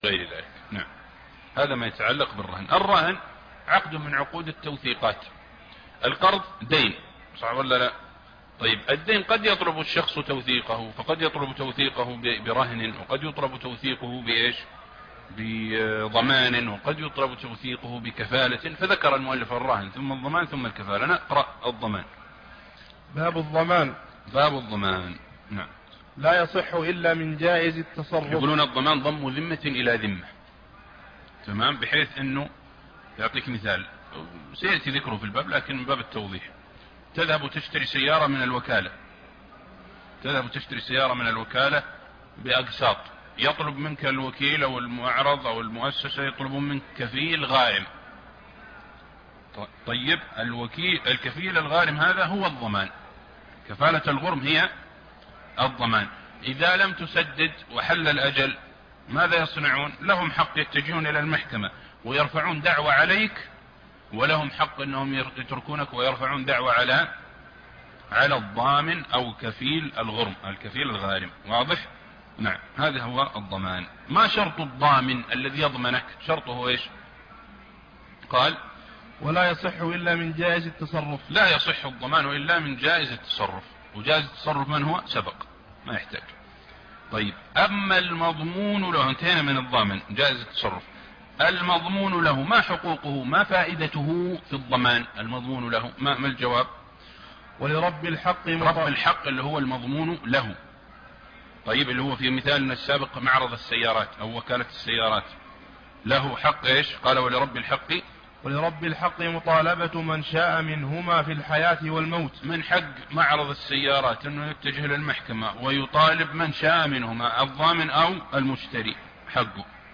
الدرس 78- شرح زاد المستقنع ( باب الضمان)